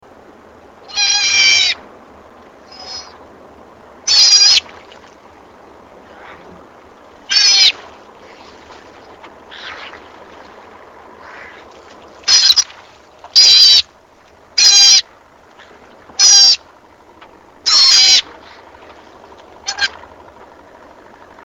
Sea Otter Vocalization 1
Tags: South America journey